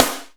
SNARE26.wav